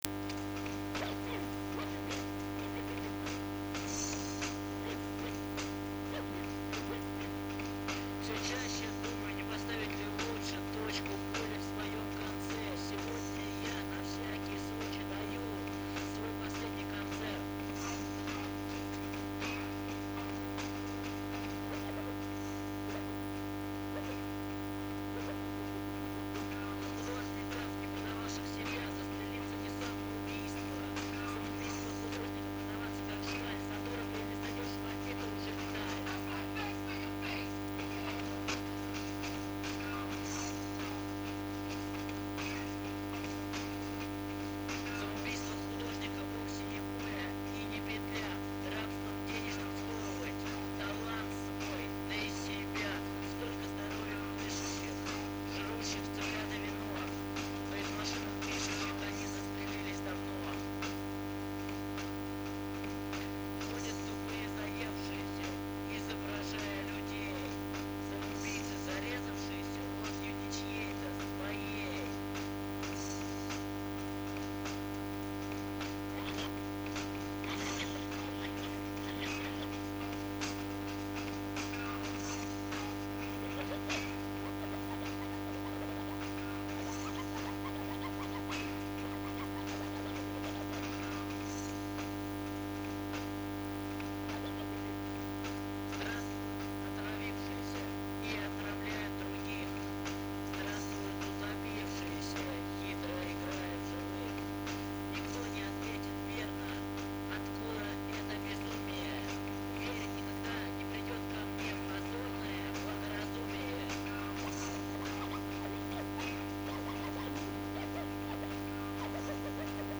Scratching: